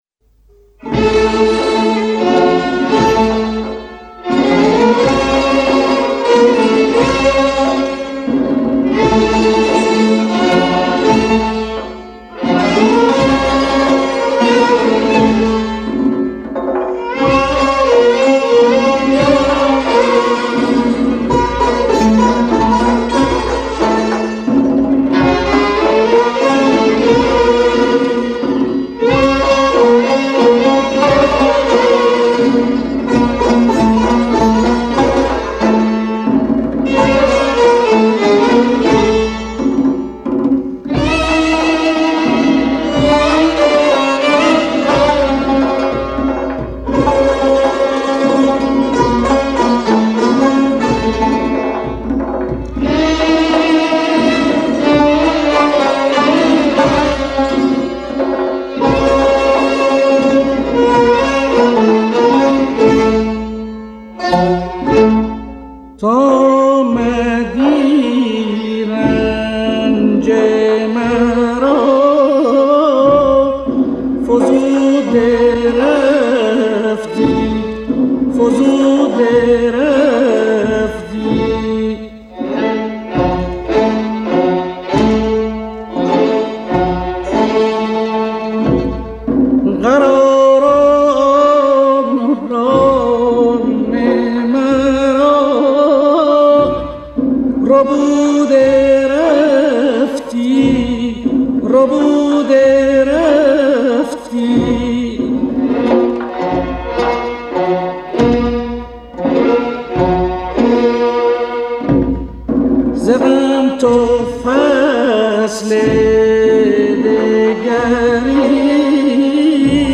در مقام سه گاه